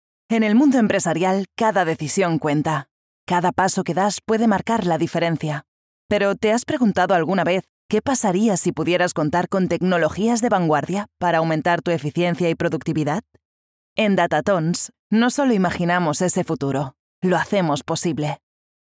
Voix
Commerciale, Naturelle, Amicale, Chaude, Douce
Corporate